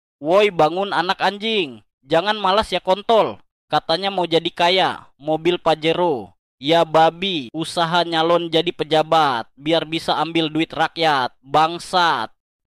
Genre: Nada dering alarm